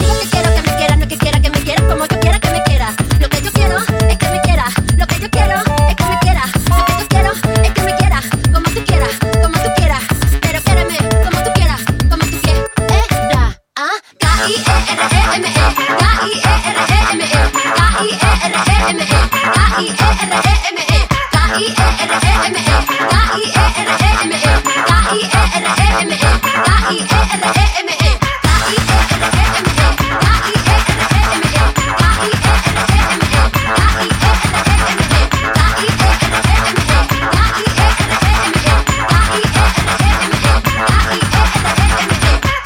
Genere: latin pop, latin house, latin tribal, bachata